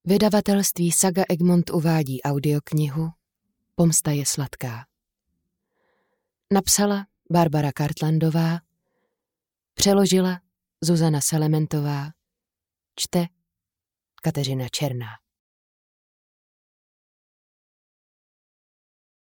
Pomsta je sladká audiokniha
Ukázka z knihy